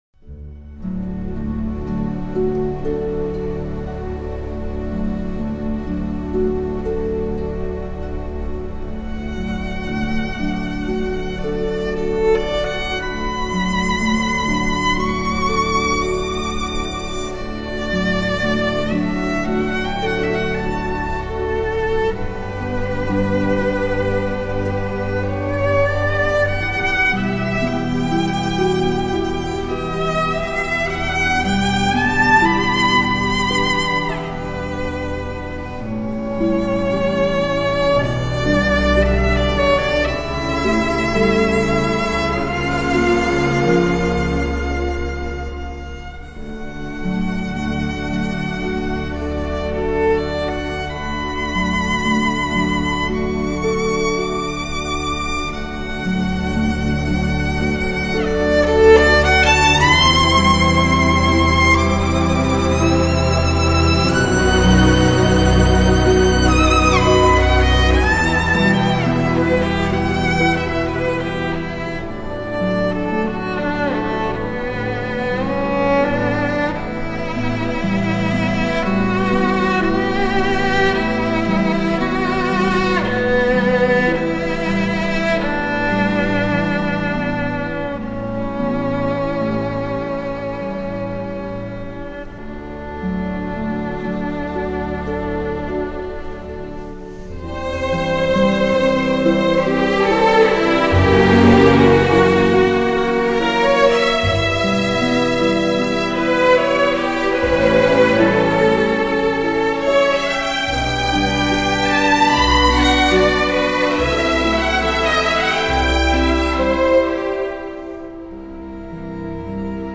这是一张精致古典小提琴的绝版完美专辑！
毫无暇疵的小提琴散发出如火焰般的强烈吸引力，令你完全沉醉于此。